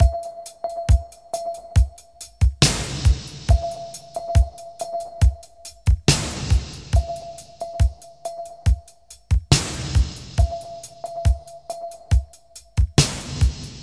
• 69 Bpm 80's Reggae Drum Loop Sample C Key.wav
Free drum loop - kick tuned to the C note. Loudest frequency: 2537Hz
69-bpm-80s-reggae-drum-loop-sample-c-key-Pud.wav